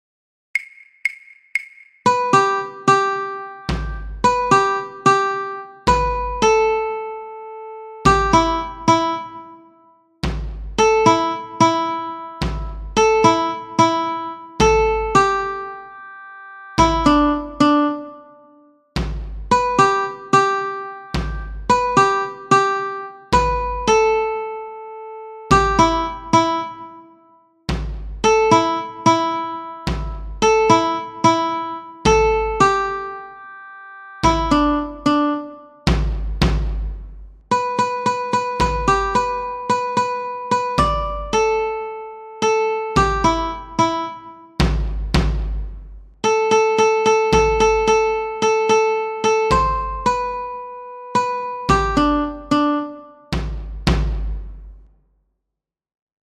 Once you are fluent in keeping the beat, examine the score and use claves to follow the slapping line.